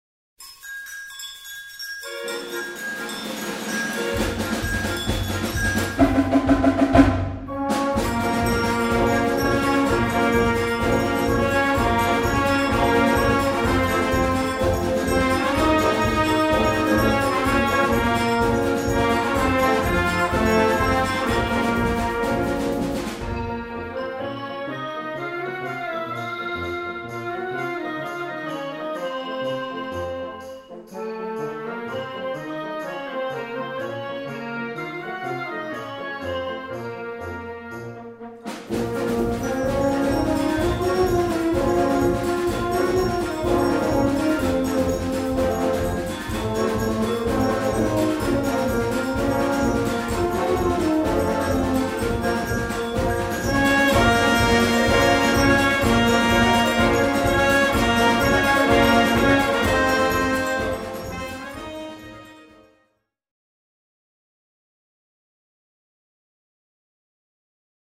A4 Besetzung: Blasorchester Zu hören auf